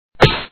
OpenTheDoor.wav